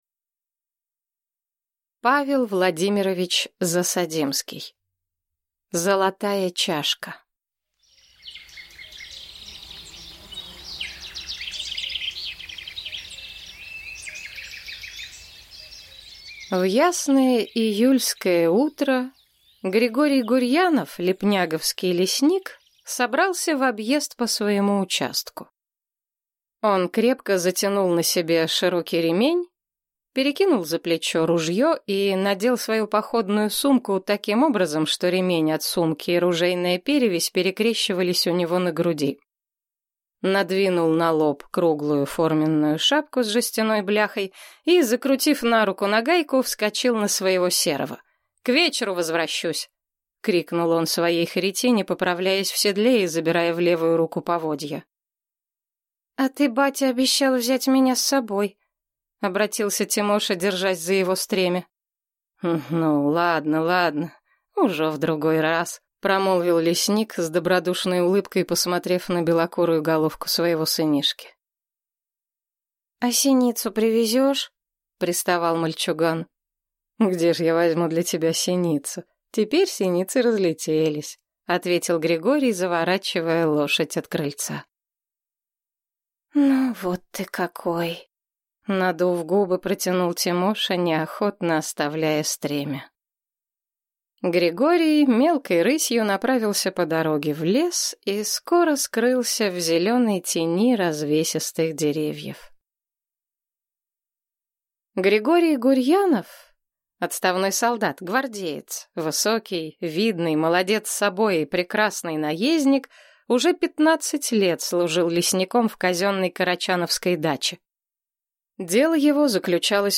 Аудиокнига Золотая чашка | Библиотека аудиокниг